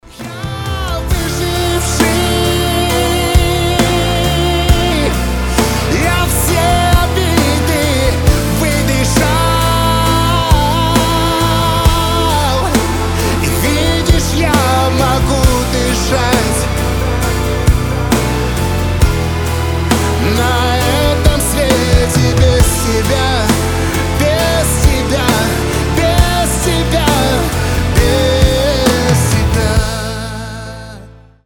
• Качество: 320, Stereo
лирика
грустные
красивый мужской голос
красивый вокал